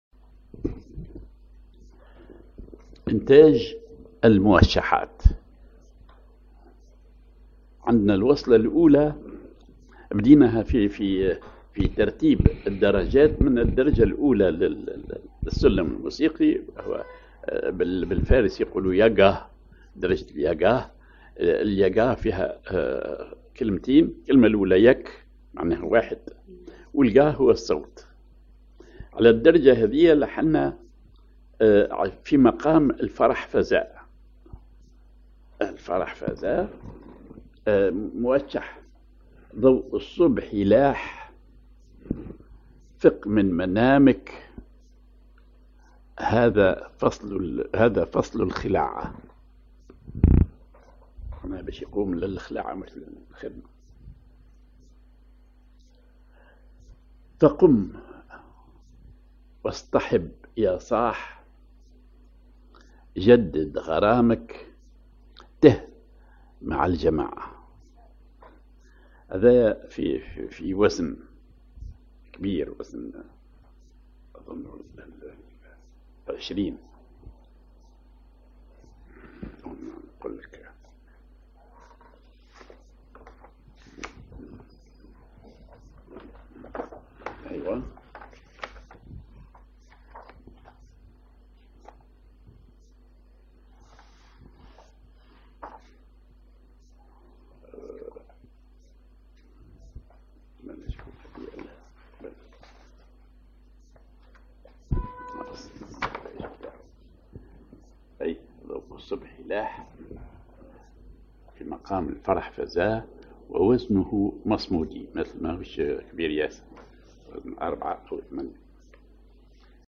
Maqam ar فرح فزا
Rhythm ar المصمودي
genre موشح